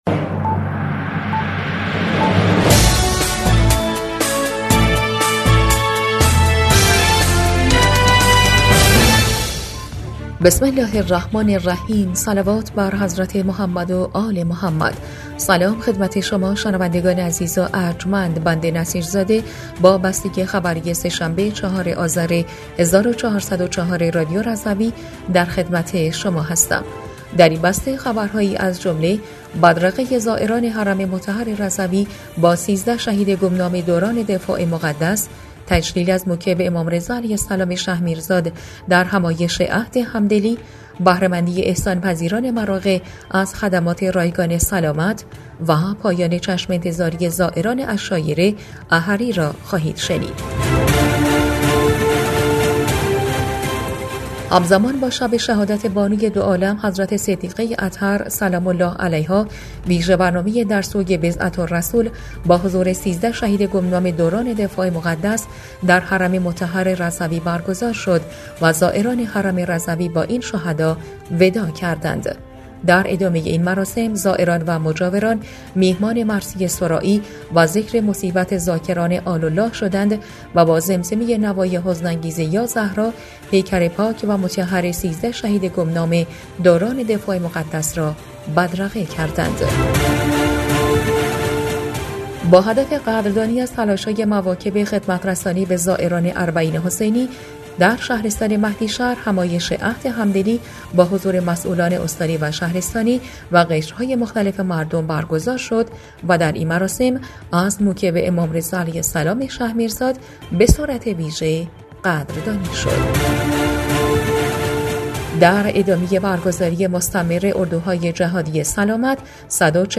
بسته خبری ۴ آذر ۱۴۰۴ رادیو رضوی؛